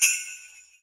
PJBELL 3.wav